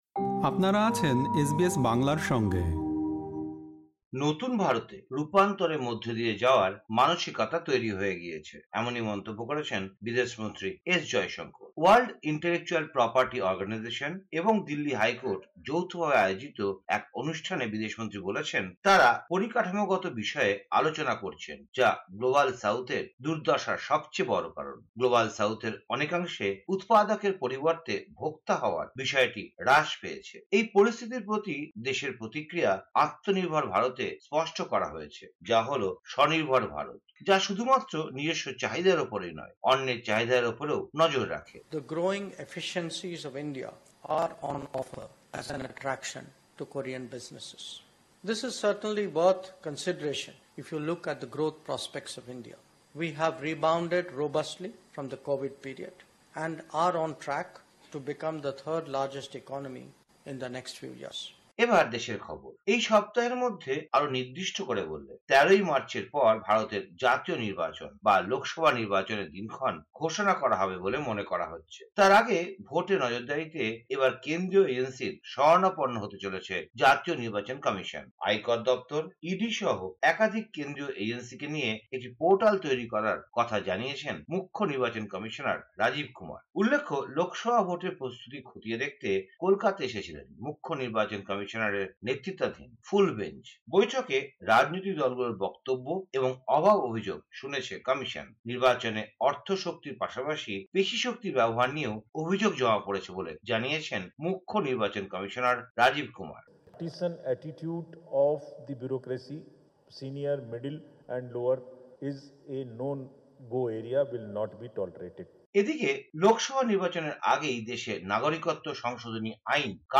ভারতের সাম্প্রতিক খবর: ১১ মার্চ, ২০২৪